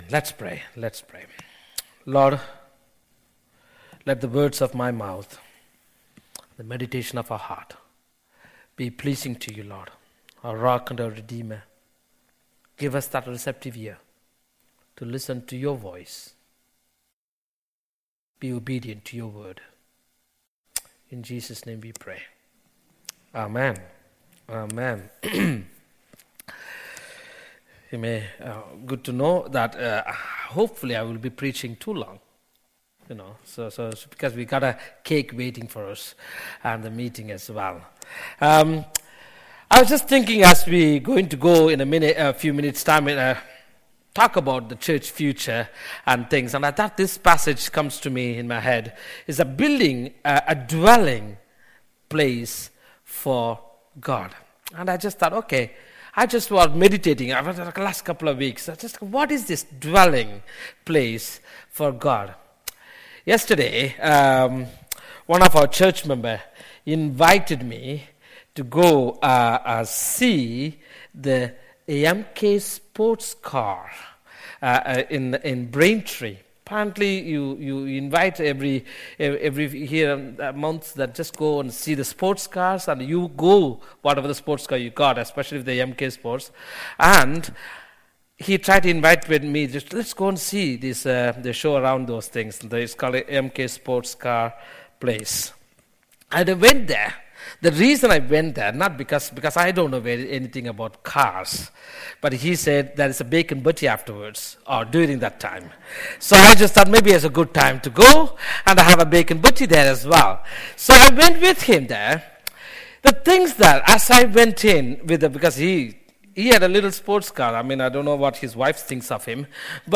The sermon is also available as an audio file.
03-23-sermon.mp3